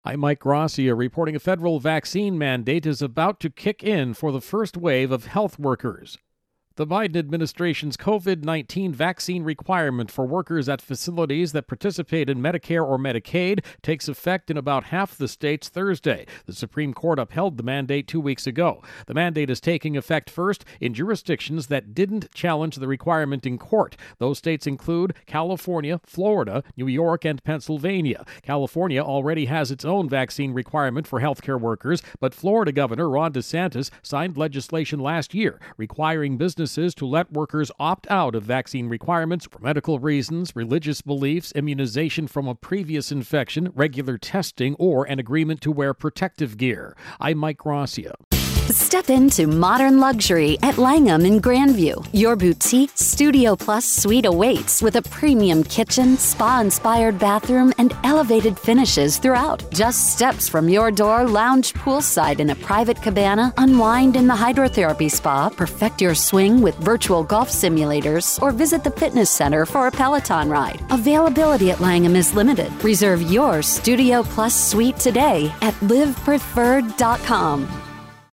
Virus Outbreak-Vaccine intro and voicer.